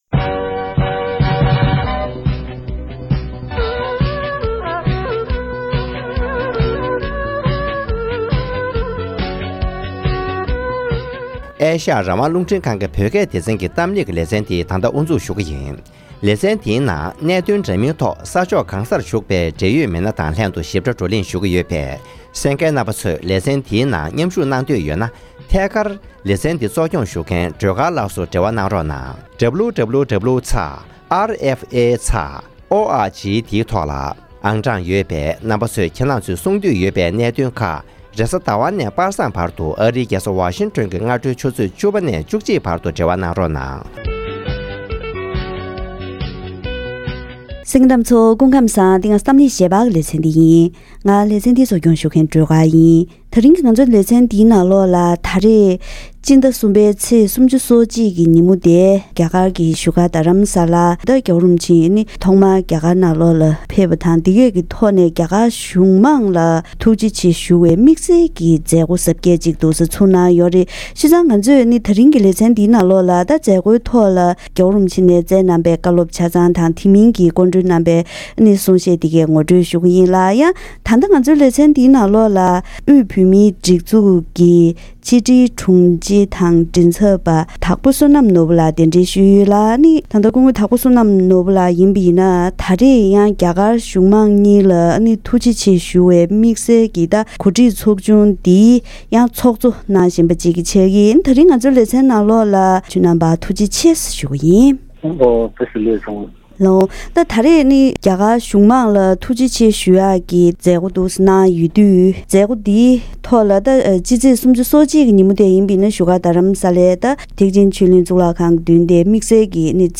༄༅༎དེ་རིང་གི་གཏམ་གླེང་ཞལ་པར་ལེ་ཚན་ནང་སྤྱི་ནོར་༸གོང་ས་༸སྐྱབས་མགོན་ཆེན་པོ་མཆོག་ནས་དབུས་པའི་བཙན་བྱོལ་བོད་མི་ཚོ་རྒྱ་གར་ནང་ཕེབས་པའི་རྗེས་རྒྱ་གར་གཞུང་གིས་གང་ཅིའི་རོགས་རམ་གནང་བར་བརྟེན་བཙན་བྱོལ་ནང་བོད་མིའི་སྒྲིག་འཛུགས་ནས་གོ་སྒྲིག་གནང་བའི་རྒྱ་གར་གཞུང་དམངས་གཉིས་ལ་ཐུགས་རྗེ་ཆེ་ཞུ་བའི་མཛད་སྒོ་ཞིག་གནང་ཡོད་་ཅིང་།